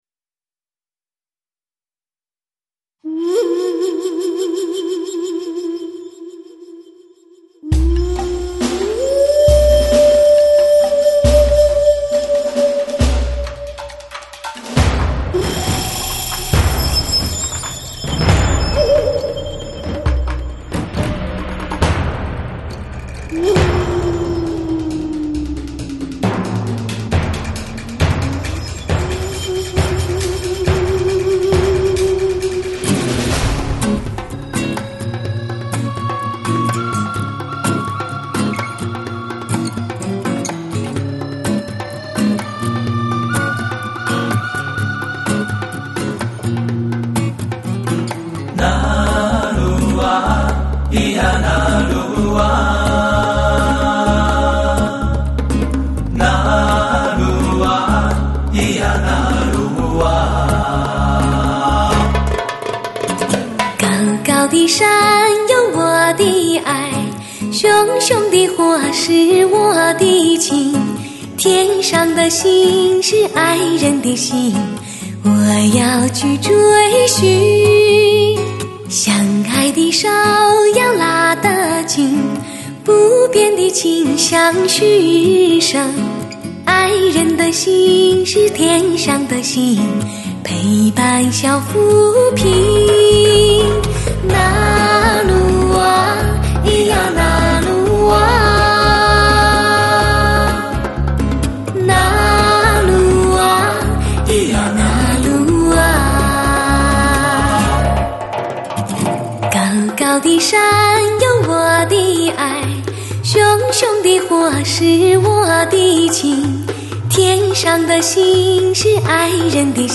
音乐风格: 流行
同样将此优点表现得淋漓尽致，除了仍旧采用DPA-3541的顶级胆麦收音，延续使用192KHZ/48bit的极
限采样技术，更在配器上面下足了大量的功夫，民族弦乐与西洋弦乐的完美结合，交响打击乐和民族打击
收录通透，细腻，高频如丝般柔滑，乃是真正试音王牌